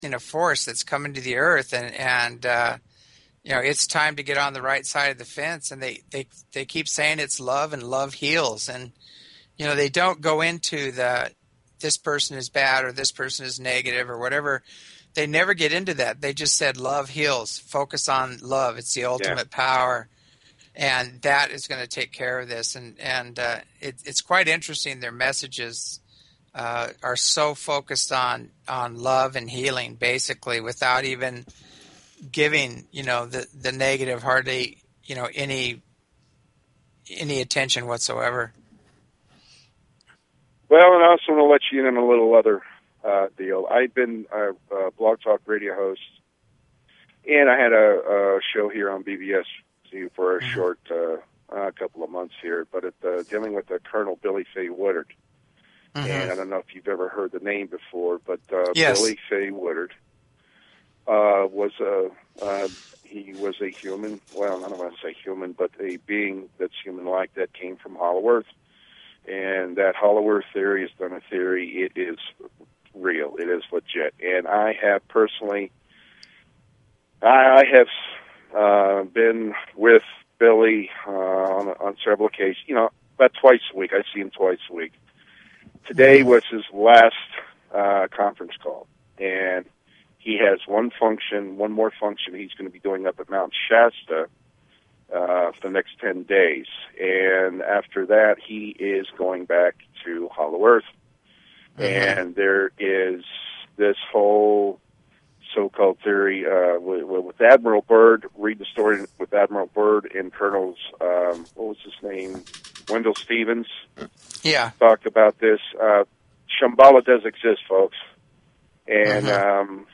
Talk Show Episode, Audio Podcast, As_You_Wish_Talk_Radio and Courtesy of BBS Radio on , show guests , about , categorized as
1st hour listener call ins